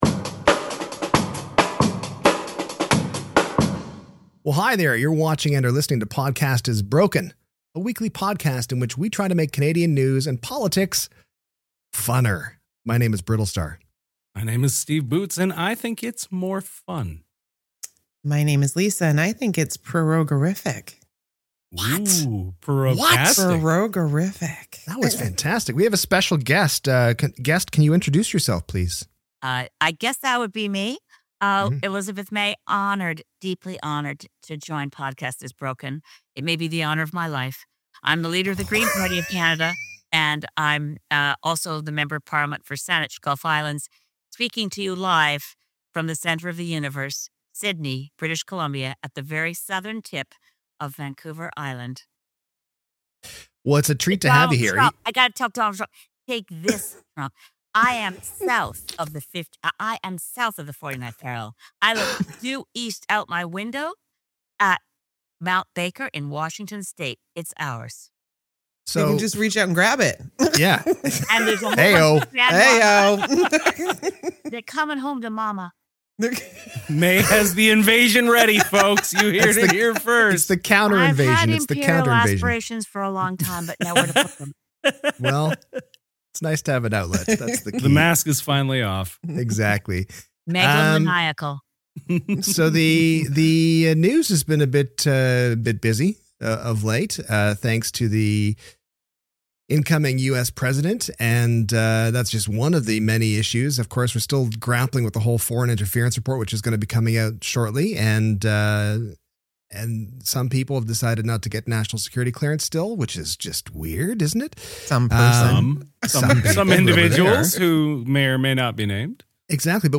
- An interview with the one, the only, the dynamo Leader of the Green Party of Canada - Elizabeth May.